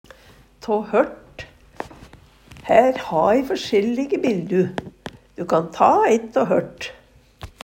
tå hørt - Numedalsmål (en-US)
DIALEKTORD PÅ NORMERT NORSK tå hørt av kvar Eksempel på bruk Her ha e førskjellige bildu.